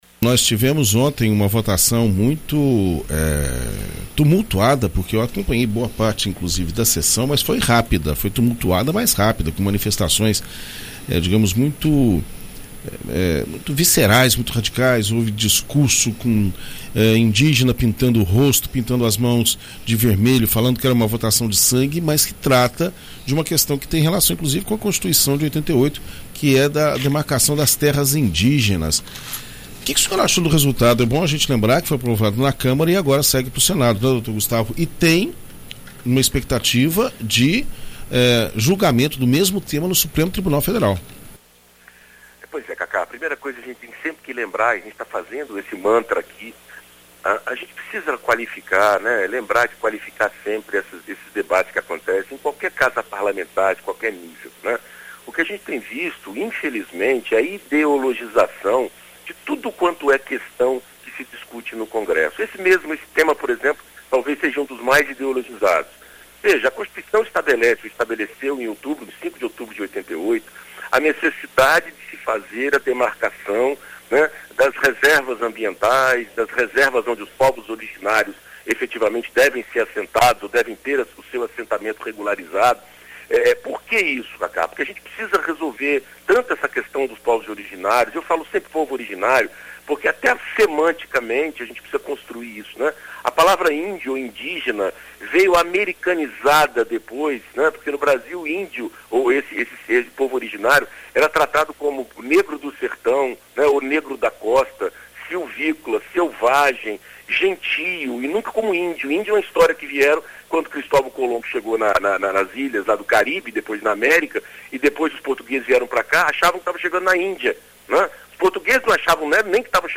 Na coluna ‘Direito Para Todos’ desta quarta-feira (31) na BandNews FM ES